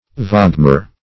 Search Result for " vaagmer" : The Collaborative International Dictionary of English v.0.48: Vaagmer \Vaag"mer\, n. [Icel. v[=a]gmeri a kind of flounder, literally, wave mare.]